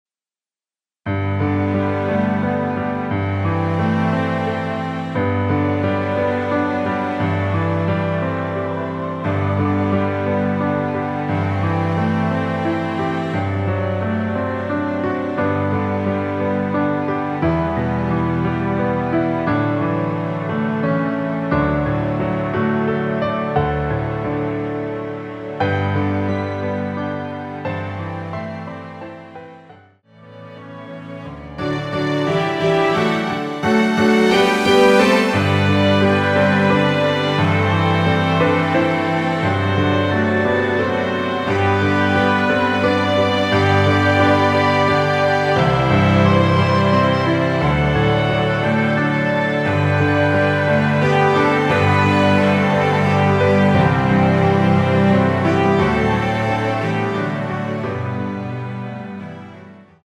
여자키 MR입니다.
처음 노래 시작 하는 부분에 노래 들어가기 쉽게 멜로디 넣어 놓았습니다.(첫음이 시로 시작됩니다.)
앞부분30초, 뒷부분30초씩 편집해서 올려 드리고 있습니다.
중간에 음이 끈어지고 다시 나오는 이유는